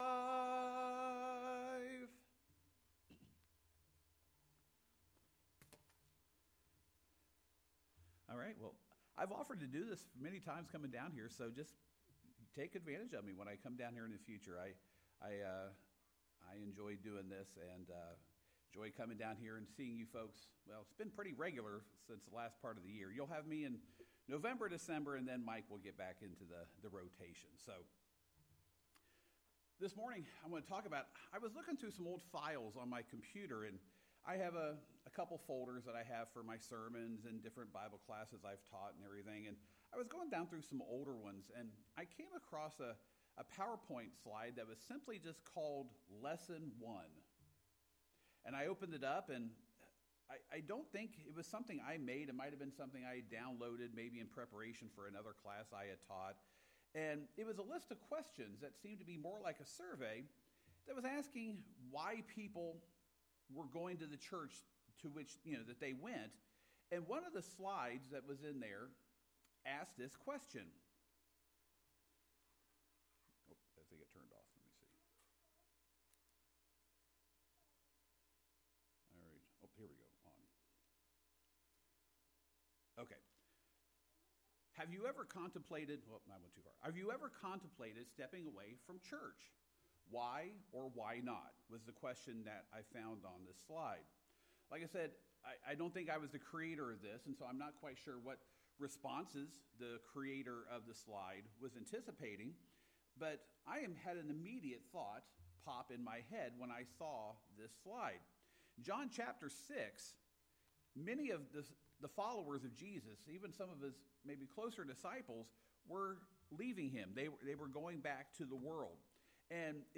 The sermon’s goal is to remind believers that true discipleship requires commitment, acceptance of Christ’s teachings even when difficult, and dependence on Him as the sole source of eternal life.